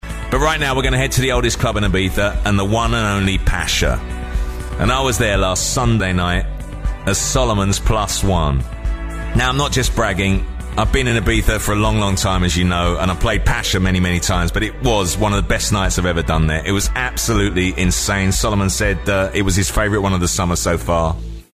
英語圏の発音ではパシャ（Pete Tong）、スペイン語圏ではパチャ